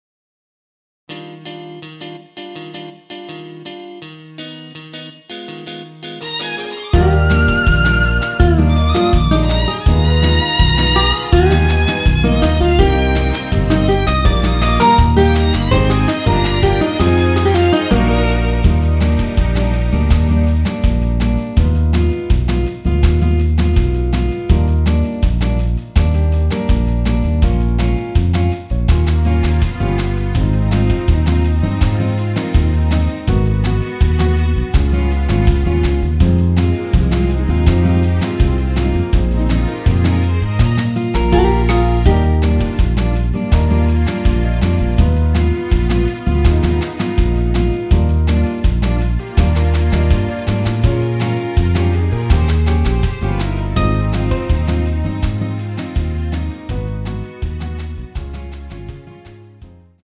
원키에서(+2)올린 MR입니다.
Eb
앞부분30초, 뒷부분30초씩 편집해서 올려 드리고 있습니다.
중간에 음이 끈어지고 다시 나오는 이유는